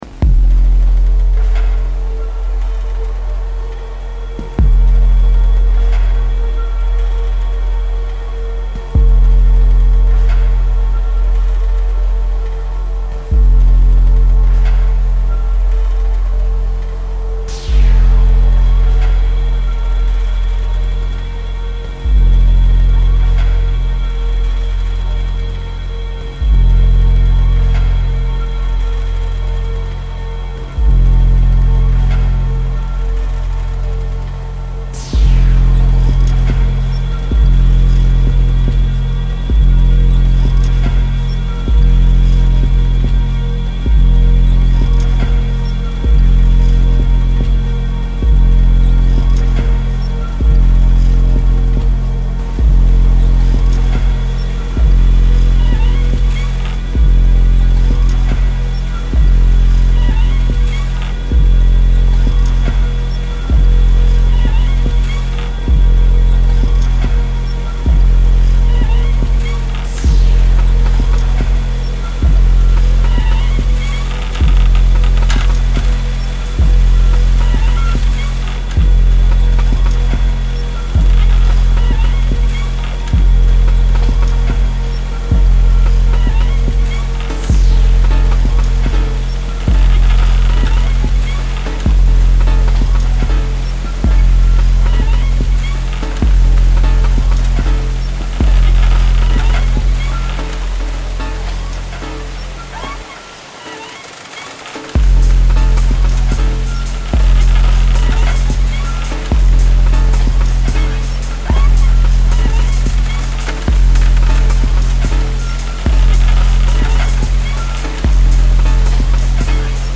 The bamboo recording felt like one of those moments.
Ilha Grande soundscape reimagined